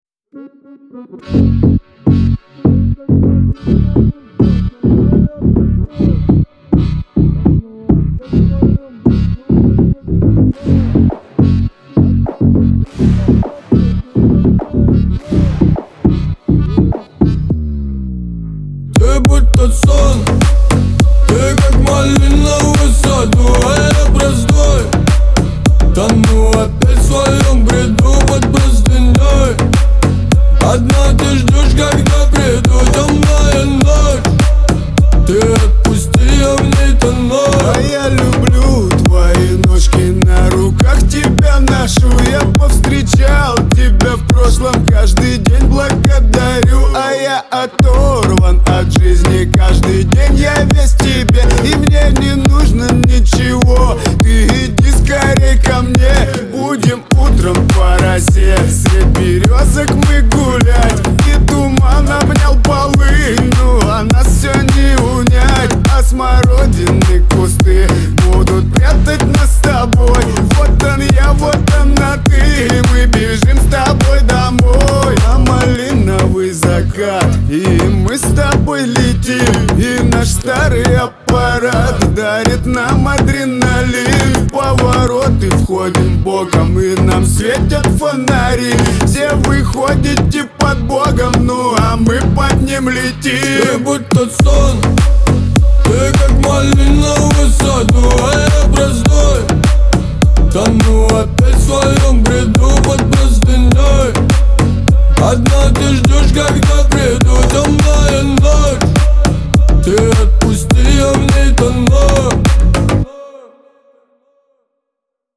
дуэт
ХАУС-РЭП , Лирика , Шансон